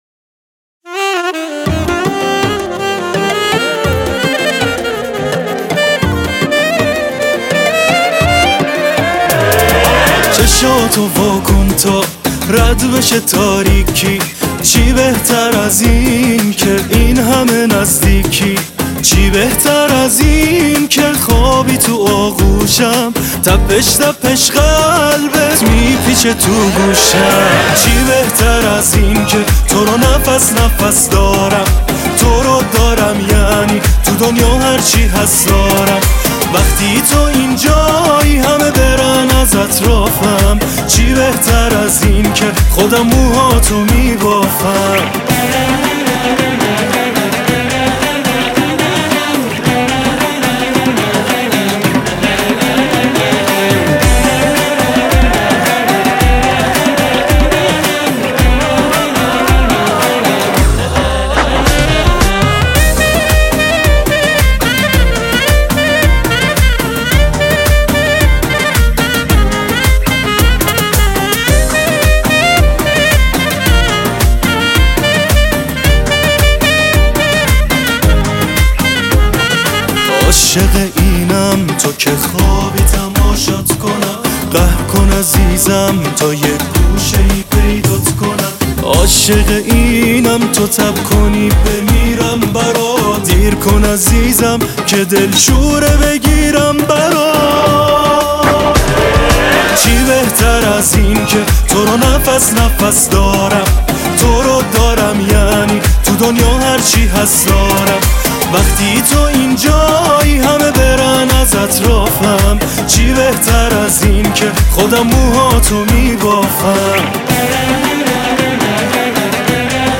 موزیک شاد
• آهنگ شاد